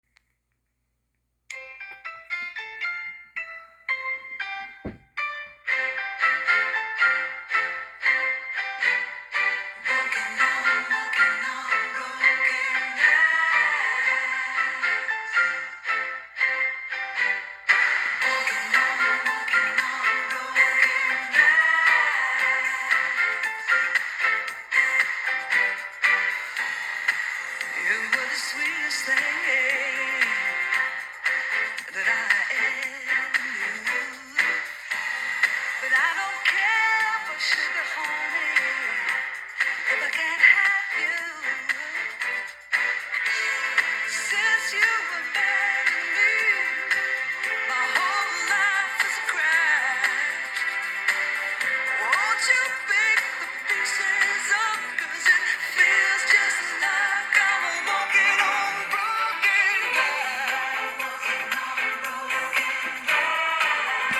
My POV, as I said, and you're welcome to yours<3<3 xo P.s. enjoy the music!